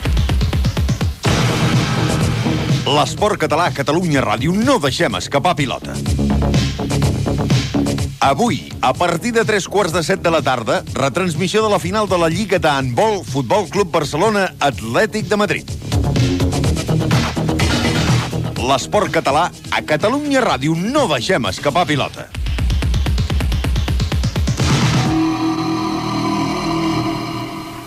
Promo de la transmissió d'Handbol "No deixem escapar pilota".
FM